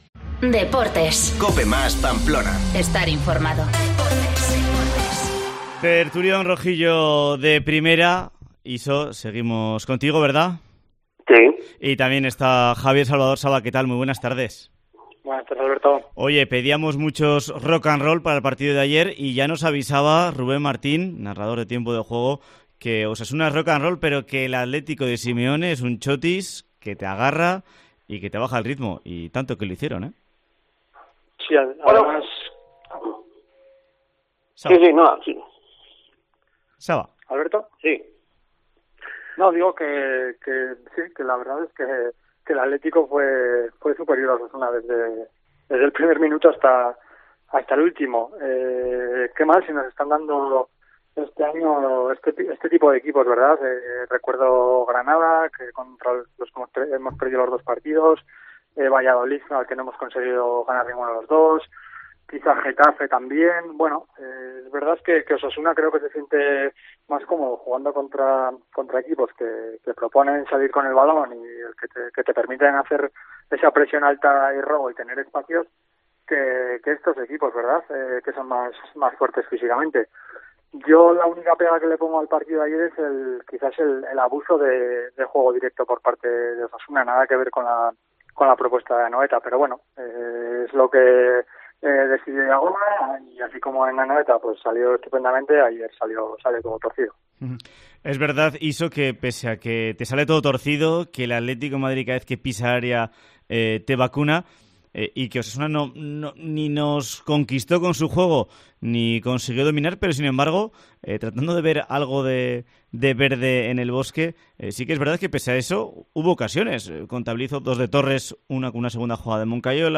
Tertulión rojillo de Primera tras el Osasuna-Atlético: "Bofetada de realidad"